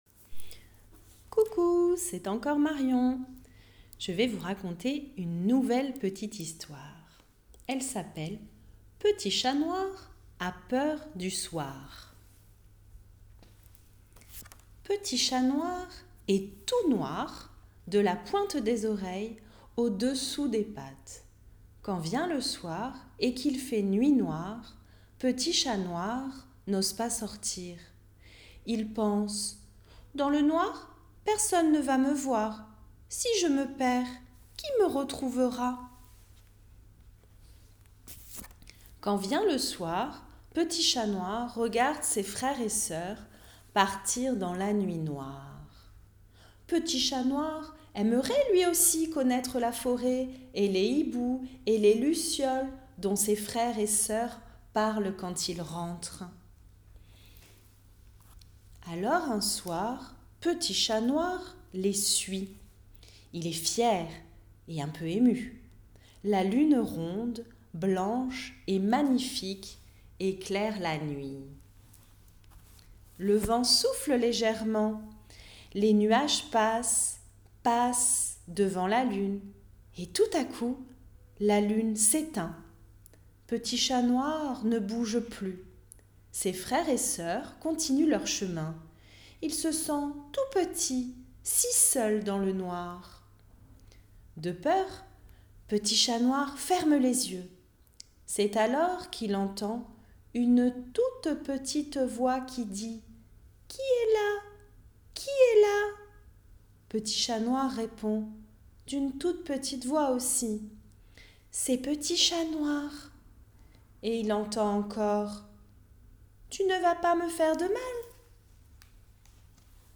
Une sélection de différents contes audio et histoires animées, vous sont proposés. Contés avec entrain et dynamisme